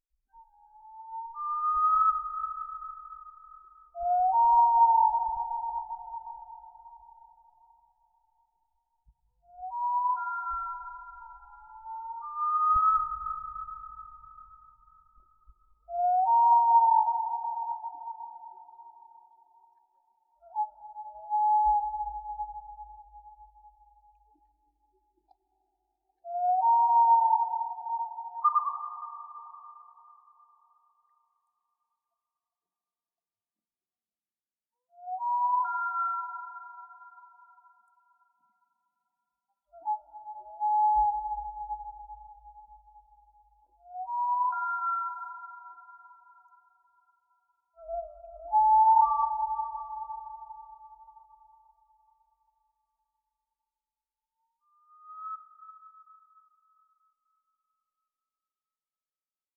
Птицы звуки скачать, слушать онлайн ✔в хорошем качестве